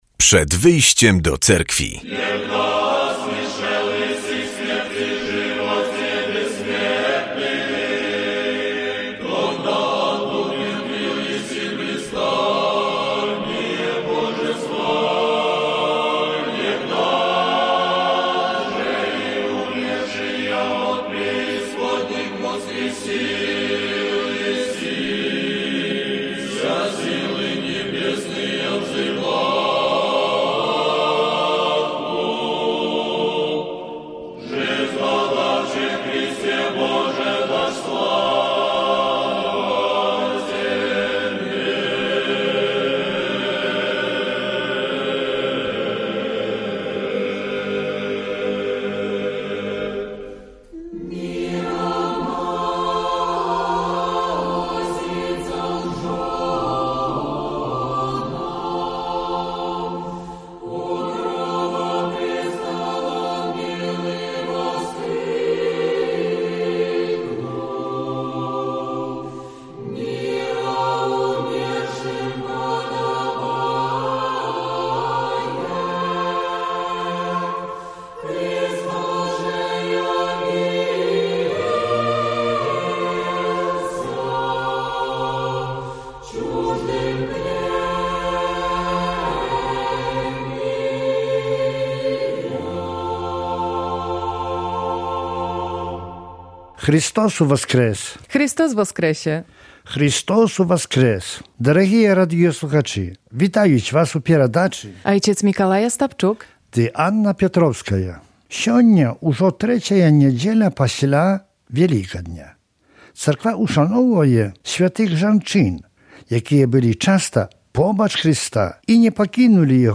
W audycji usłyszymy kazanie na temat niedzielnej Ewangelii i informacje z życia Cerkwi prawosławnej. Spotkamy się także z pielgrzymami, którzy uczestniczyli w uroczystościach ku czci św. męczennika Gabriela w monasterze w Zwierkach.